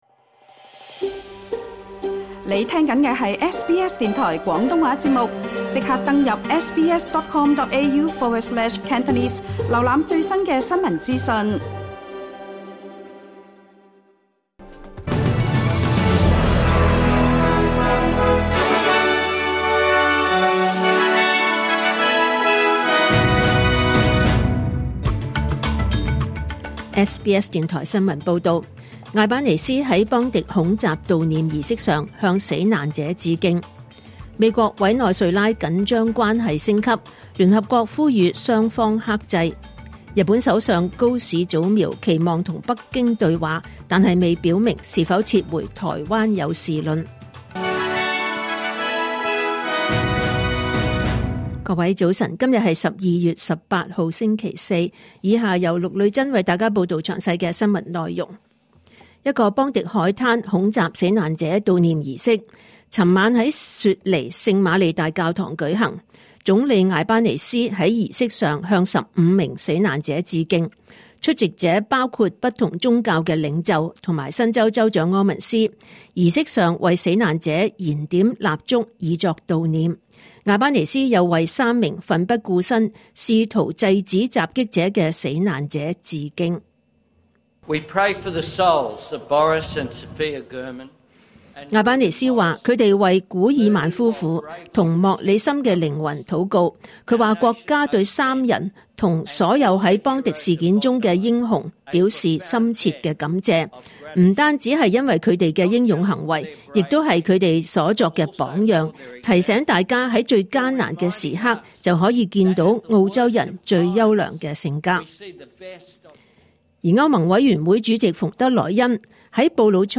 2025年12月18日SBS廣東話節目九點半新聞報道。